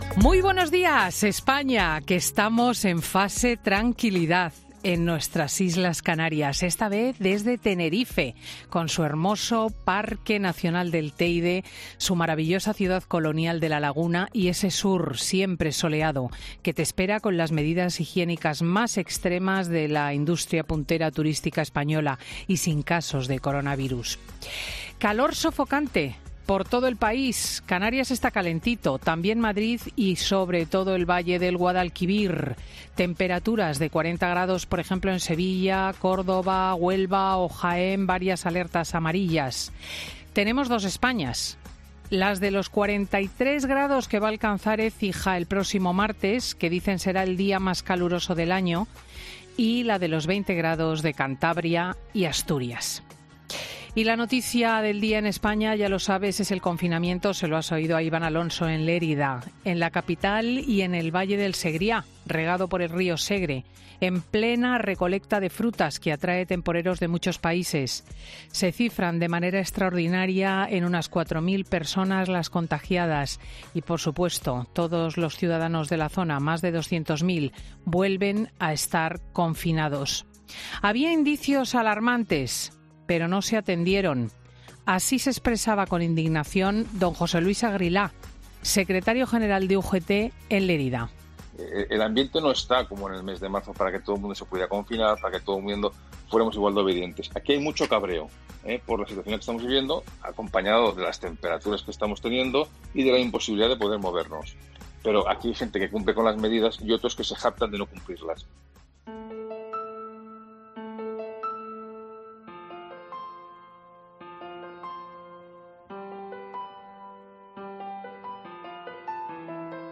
AUDIO: La presentadora de 'Fin de Semana' analliza la situación en España ante los nuevos brotes de coronavirus y las elecciones gallegas y vascasa